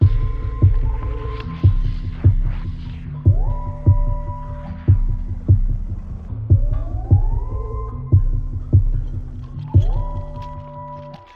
Attention Ambience Loop.wav